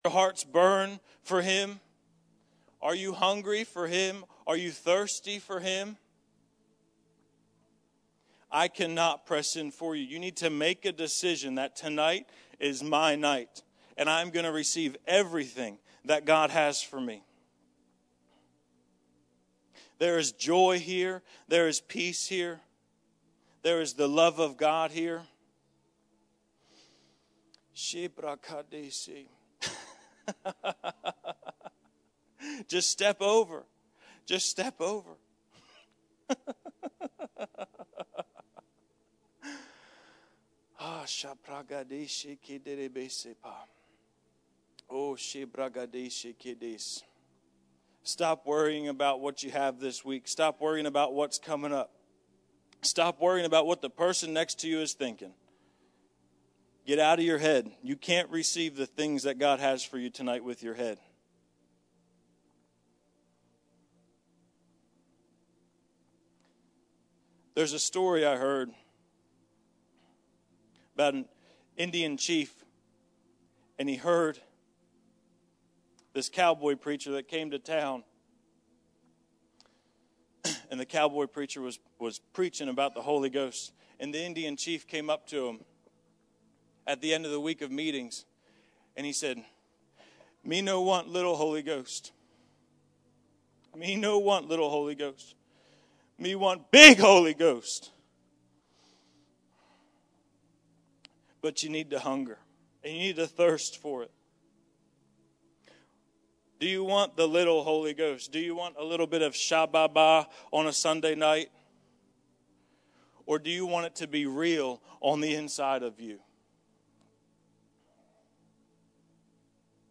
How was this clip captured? Sunday Evening September 24th